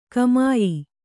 ♪ kamāyi